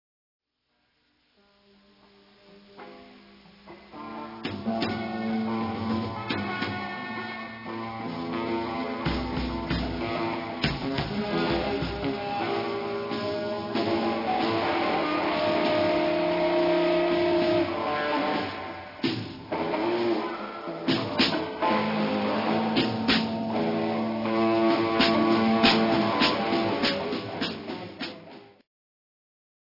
カナダ・ジャズ＆ノイズ界の至宝
84年にカセットのみで発売されていた幻の音源が初CD化！